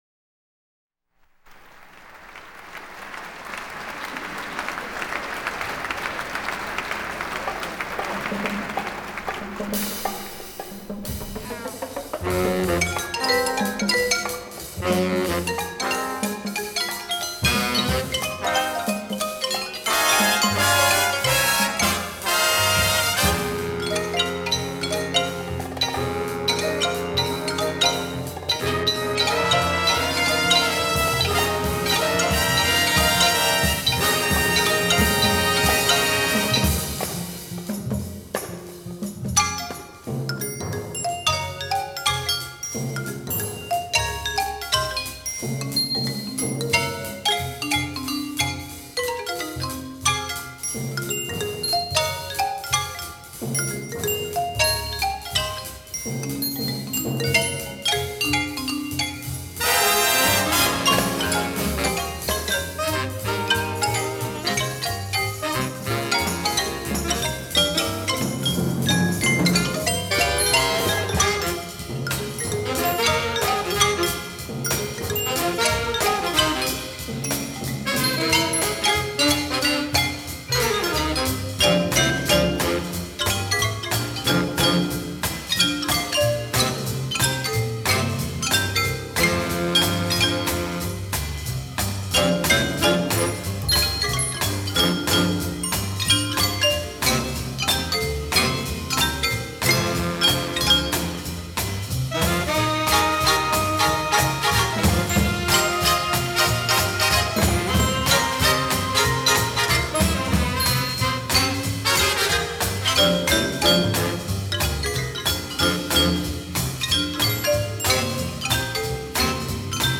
Piano
Vocals
Drums
Percussion
Saxophone
Trombone
Trumpet